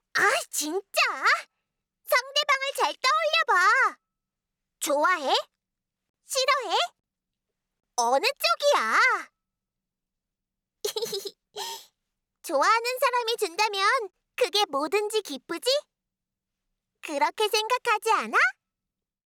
かわいい女の子